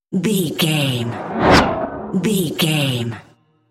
Whoosh fast bright
Sound Effects
Atonal
Fast
futuristic
tension